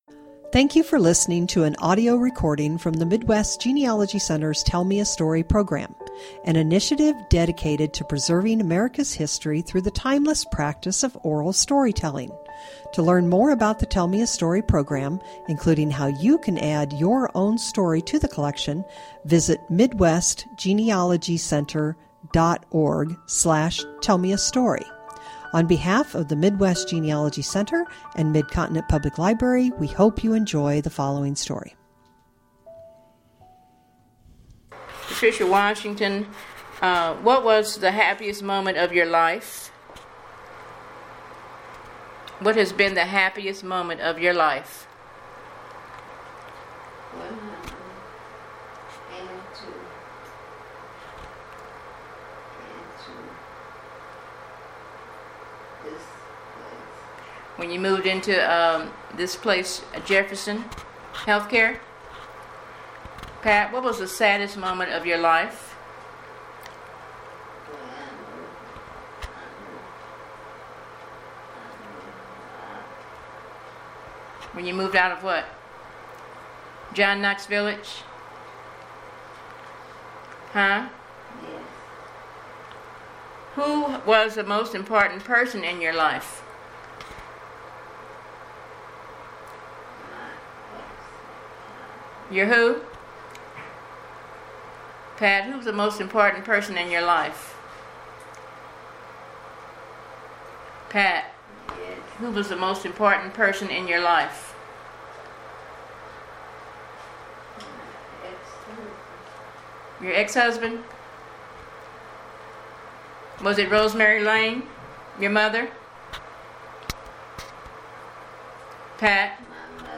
Oral History
interviewer
interviewee